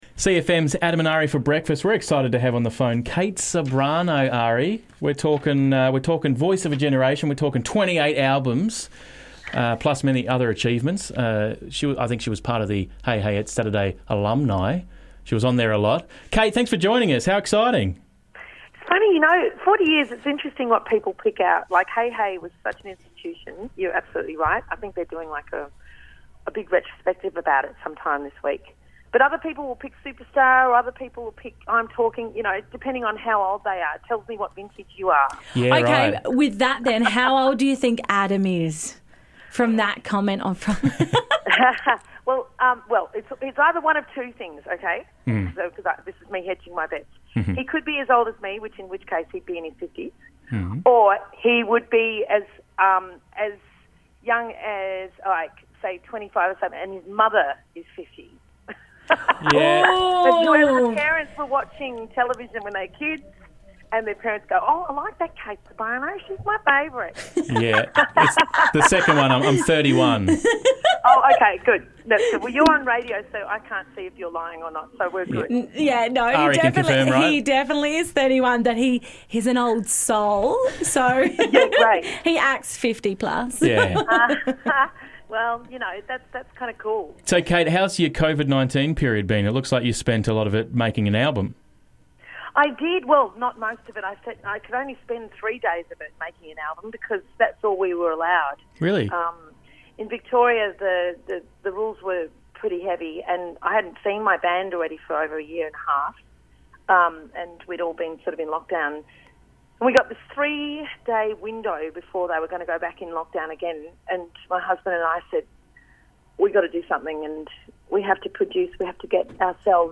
CLIP: Interview with Kate Ceberano